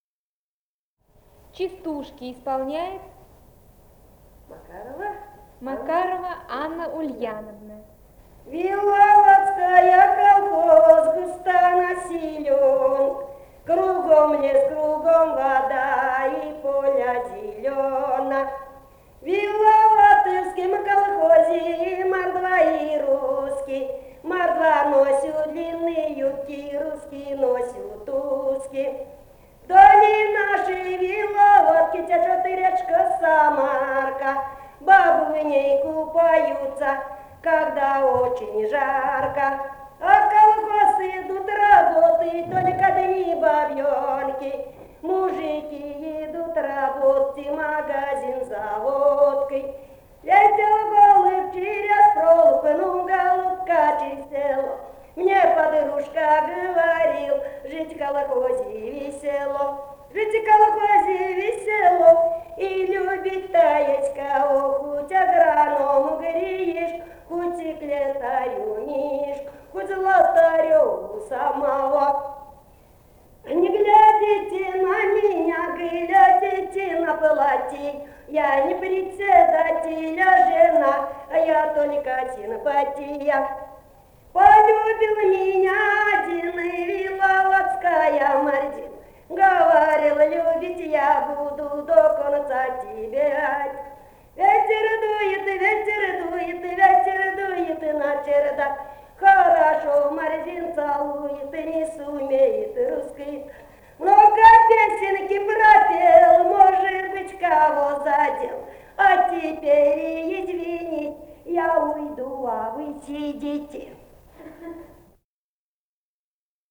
Этномузыкологические исследования и полевые материалы
Самарская область, с. Виловатое Богатовского района, 1972 г. И1316-25